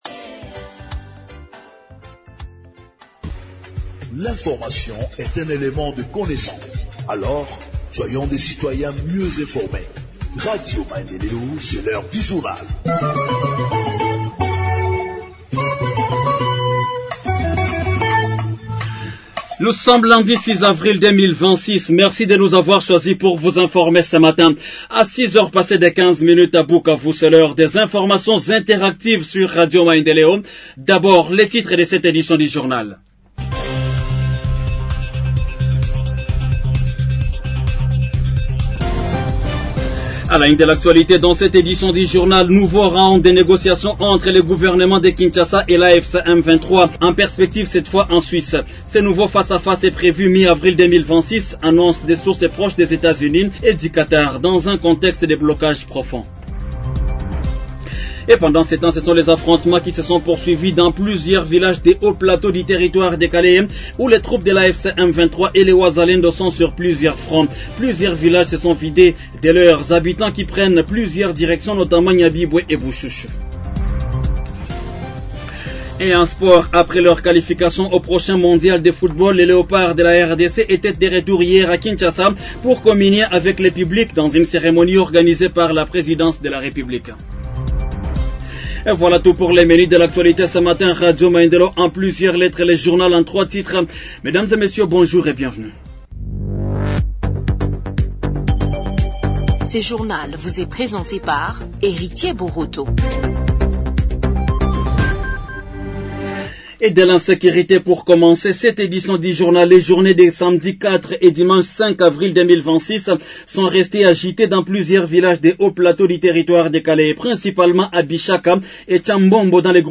Journal en Français du 06 Avril 2026 – Radio Maendeleo